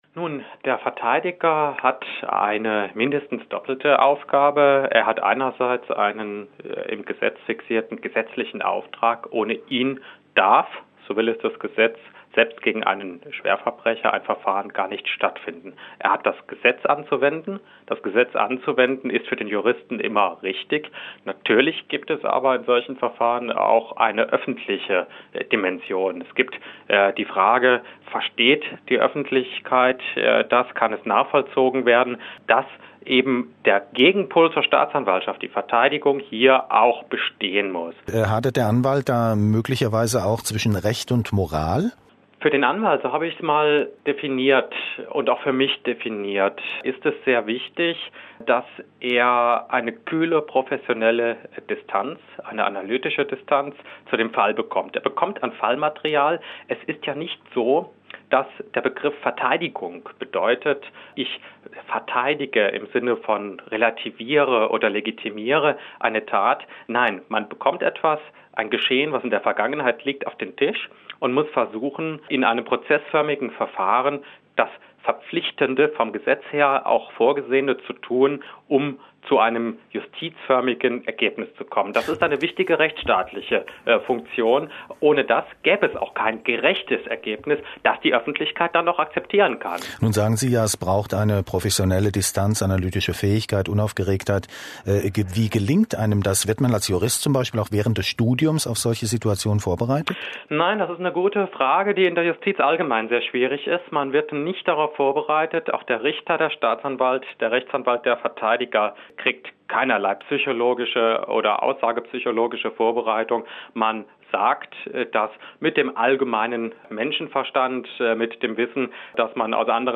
Radiointerview